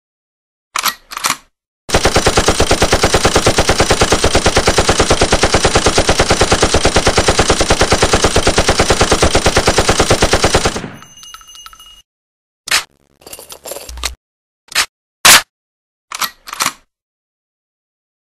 دانلود صدای رگبار 2 از ساعد نیوز با لینک مستقیم و کیفیت بالا
جلوه های صوتی